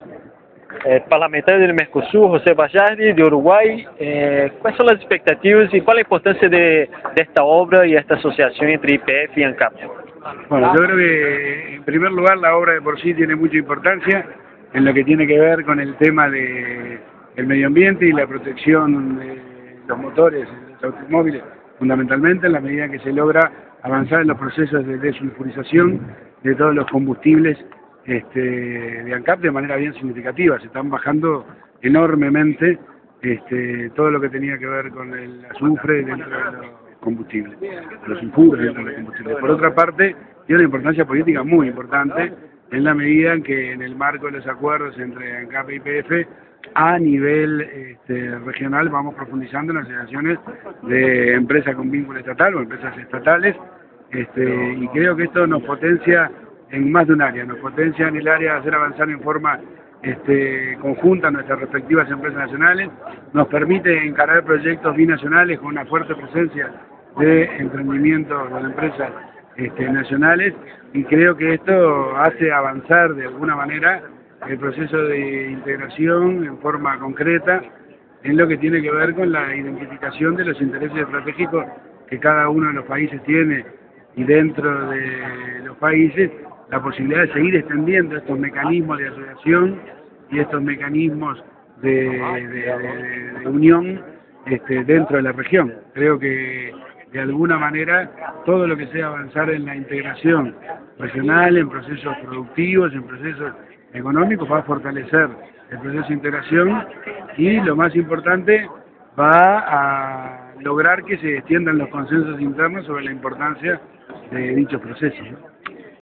Entrevista al Parlamentario del Mercosur, Jos� Bayardi.
entrevista_pepe_bayardi_ancap.mp3